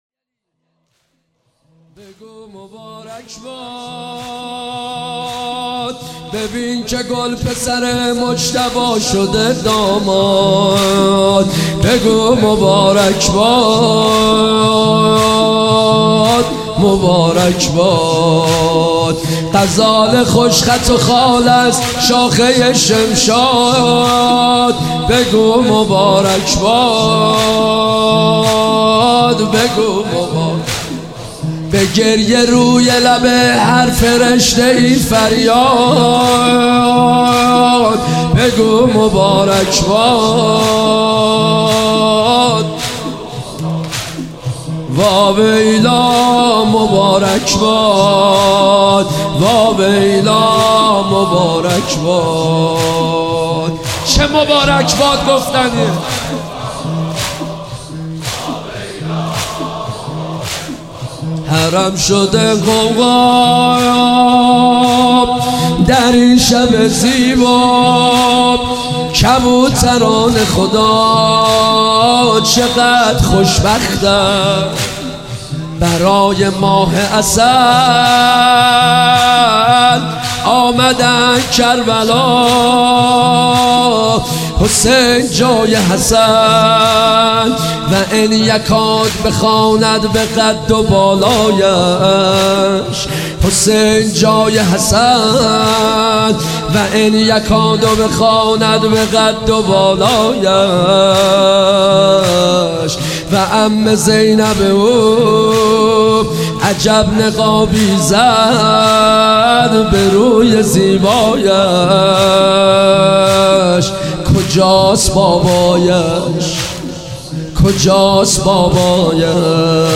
زمینه جدید محرم